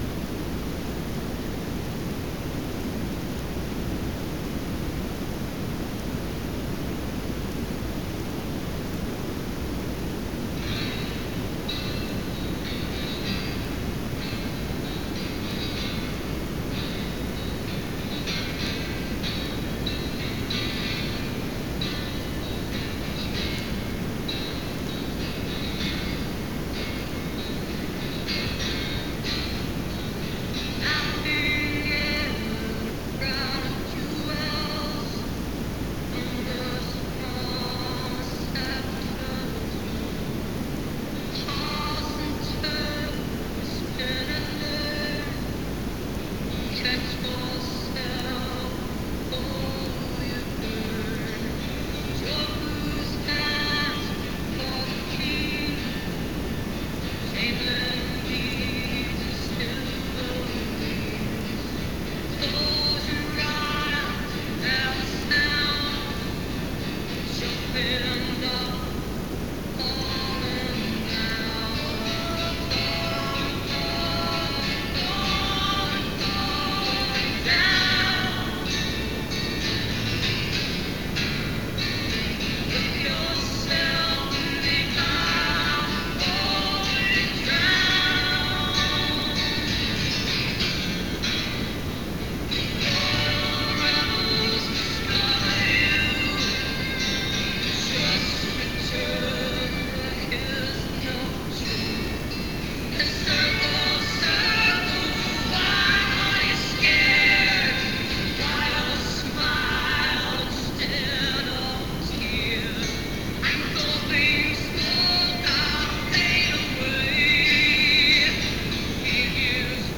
poor sound